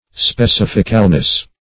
Specificalness \Spe*cif"ic*al*ness\, n. The quality of being specific.